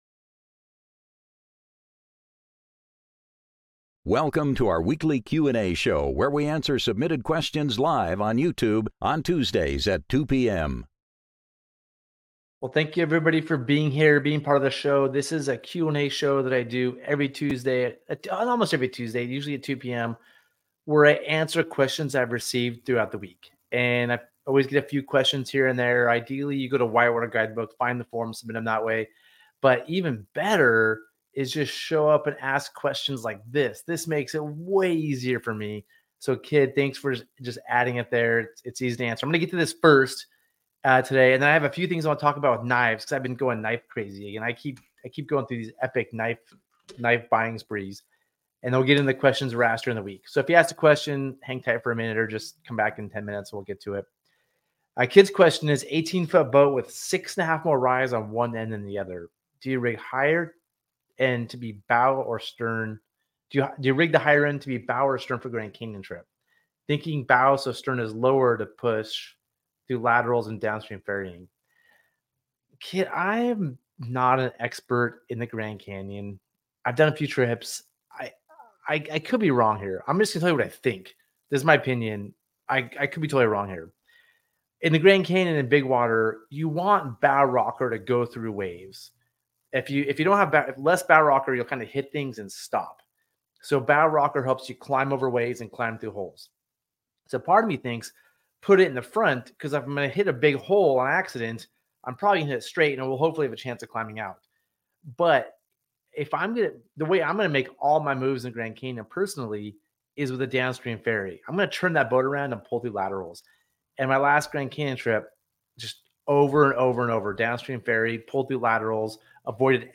Gear Garage Live Show Gear Garage Q & A Tuesday Show | October 3rd, 2023 Oct 05 2023 | 00:42:36 1x 00:00 / 00:42:36 Subscribe Share Spotify RSS Feed Share Link Embed